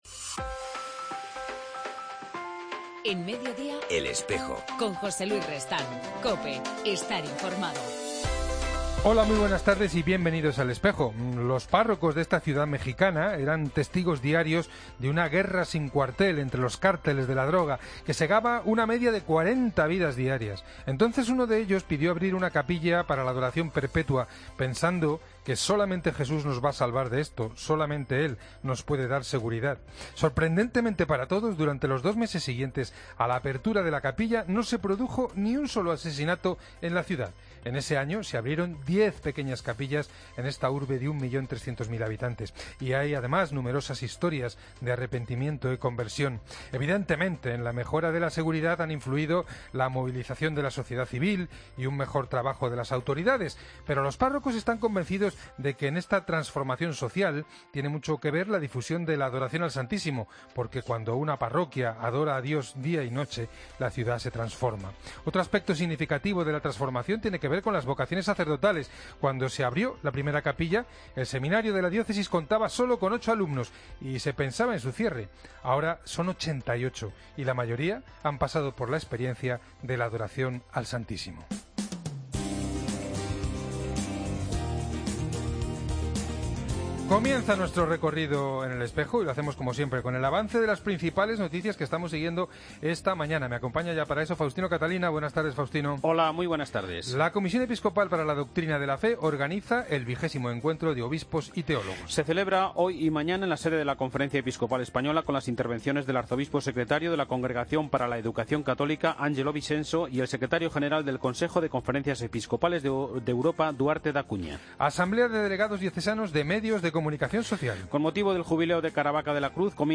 En El Espejo del 30 de enero entrevistamos a Mons. Fernando Ocáriz, nuevo prelado del Opus Dei